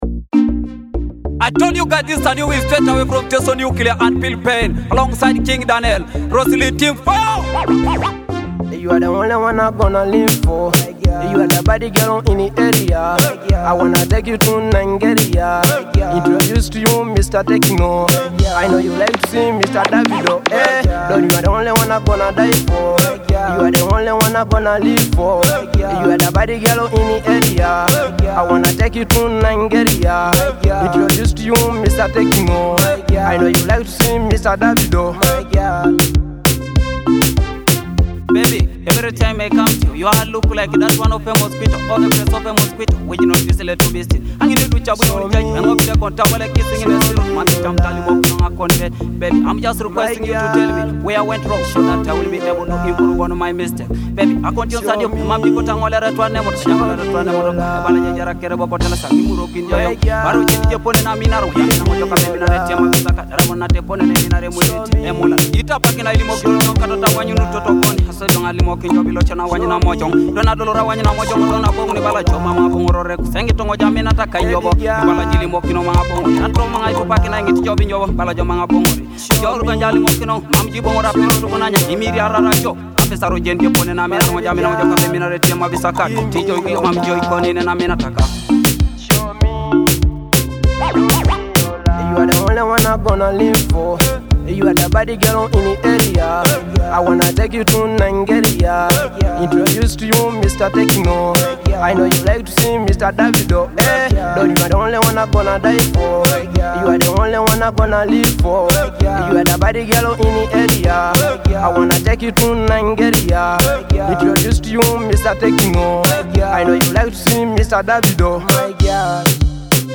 vibrant Afro-fusion track